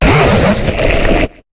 Cri de Bouldeneu dans Pokémon HOME.